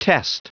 Prononciation du mot test en anglais (fichier audio)
Prononciation du mot : test